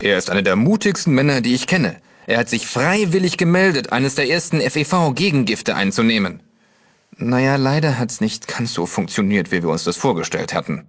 Fallout 2: Audiodialoge